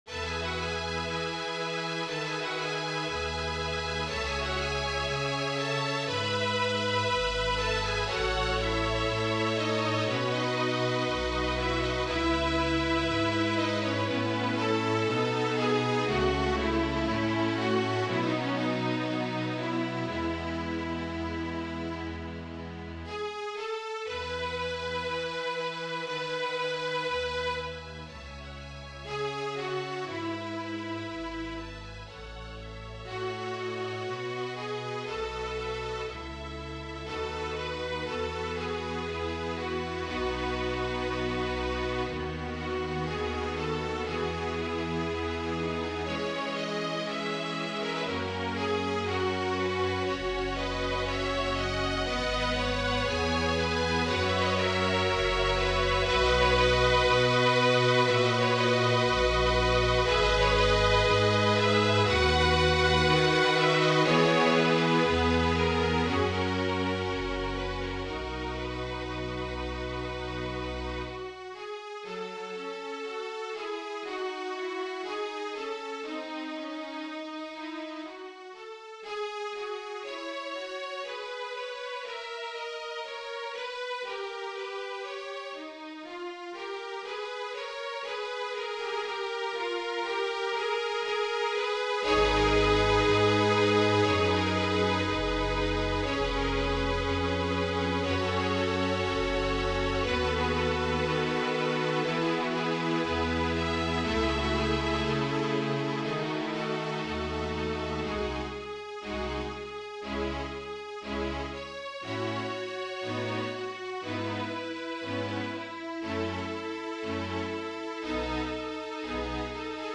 as a neo-Baroque French art song